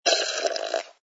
sfx_slurp_glass06.wav